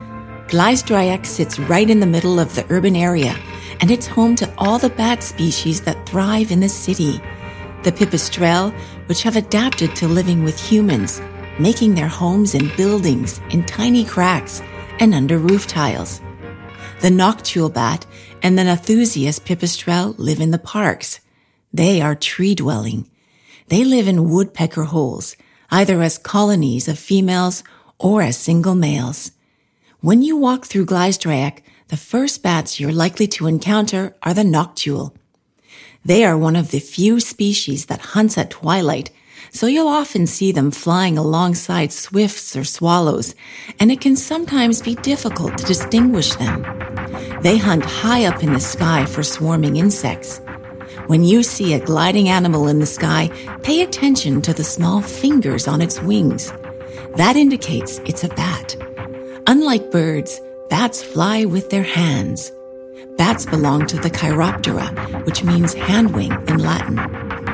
Locutor
Hablante nativo